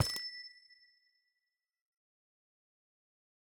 Minecraft Version Minecraft Version latest Latest Release | Latest Snapshot latest / assets / minecraft / sounds / block / amethyst / step11.ogg Compare With Compare With Latest Release | Latest Snapshot
step11.ogg